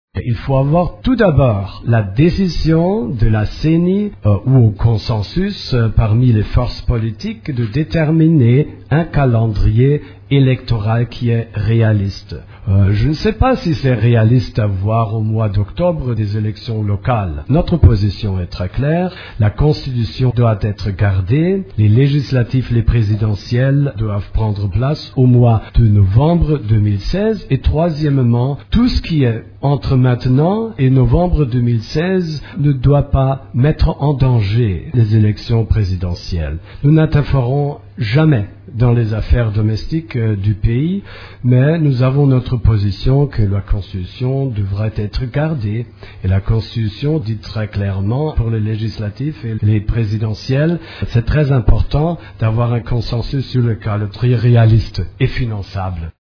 « Notre position est très claire. La Constitution devra être gardée », a affirmé mercredi 26 août le chef de la Monusco, Martin Kobler, au cours de la conférence hebdomadaire des Nations unies. Répondant aux questions des journalistes, il a indiqué que la Mission des Nations unies en RDC ne semêle pas d'affaires « domestiques » de la RDC, mais donne sa position [sur la question électorale].